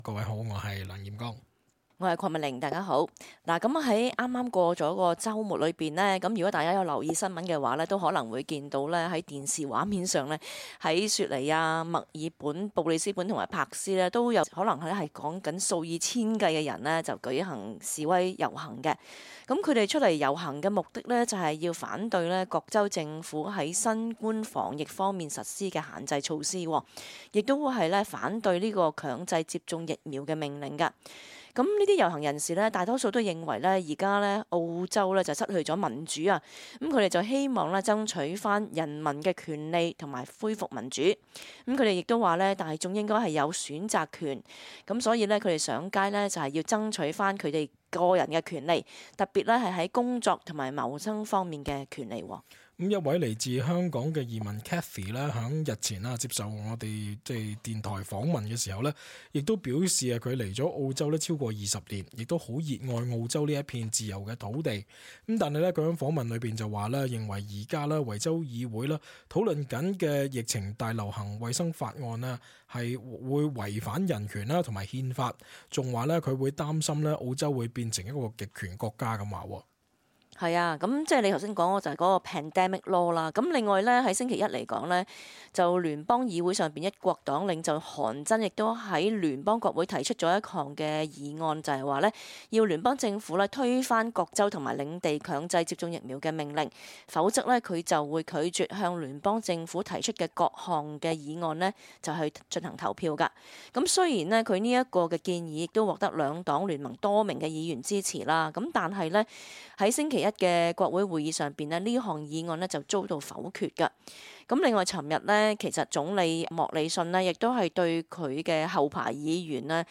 *本節目內嘉賓及聽眾意見並不代表本台立場 READ MORE 澳洲過萬人抗議強制疫苗及新冠限制 全國大批市民上街示威 墨爾本出現支持接種陣營 海外接種疫苗會回澳後需要補針嗎？
cantonese_-_talkback_-_nov_25_-final_upload.mp3